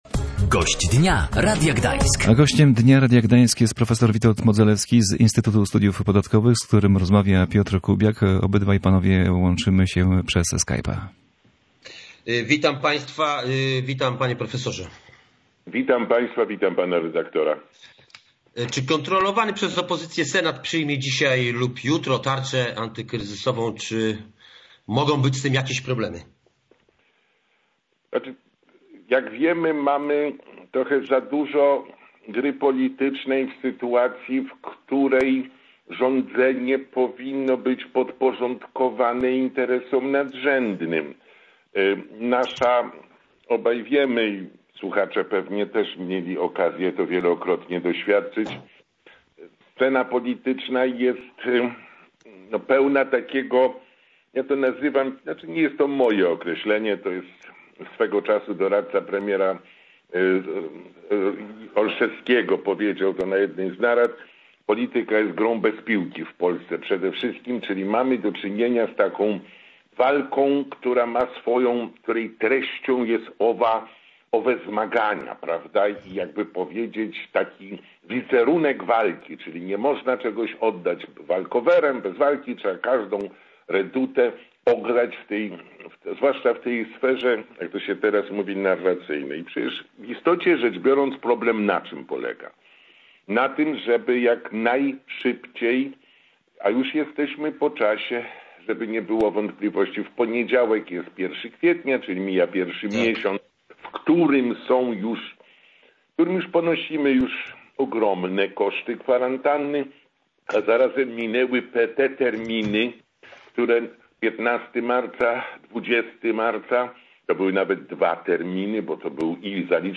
Czy mimo tego uda się przegłosować ustawy, związane z tarczą antykryzysową? I jak można ocenić dotychczasowe działania rządu w sprawie wsparcia dla polskiej gospodarki? Gościem Dnia Radia Gdańsk był profesor Witold Modzelewski z Instytutu Studiów Podatkowych.